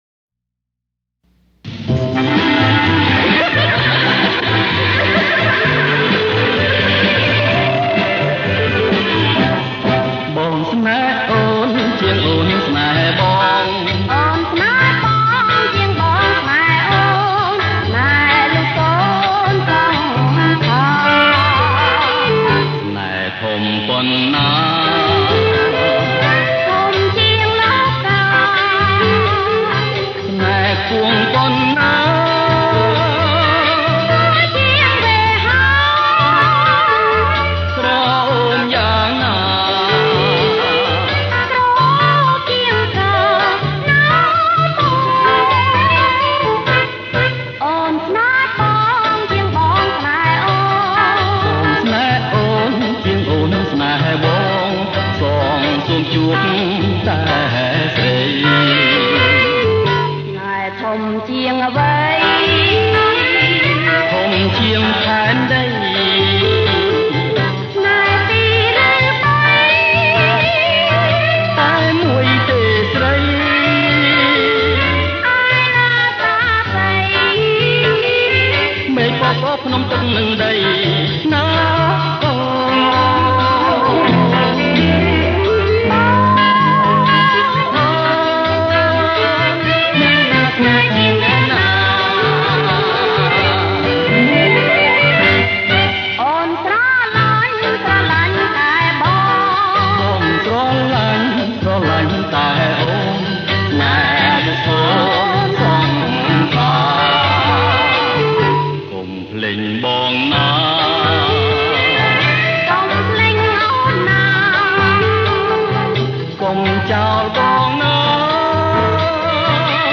• ប្រគំជាចង្វាក់ Bolero Jerk